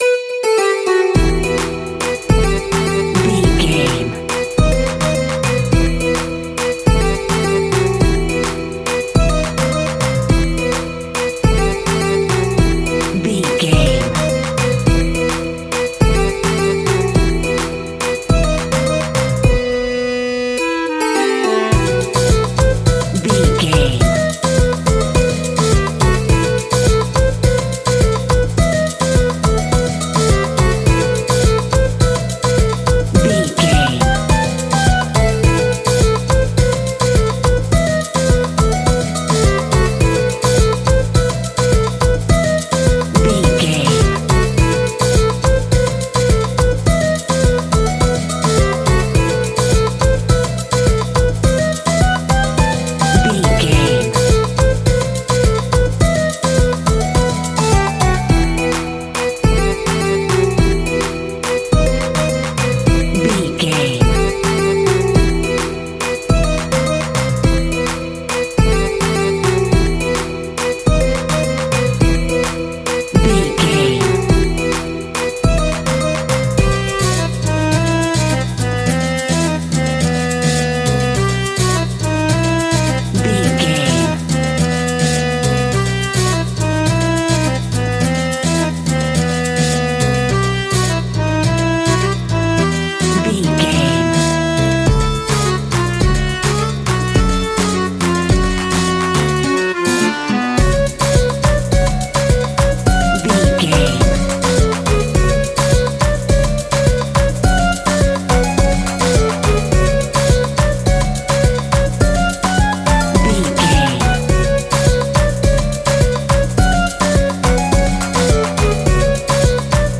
Aeolian/Minor
sitar
bongos
sarod
tambura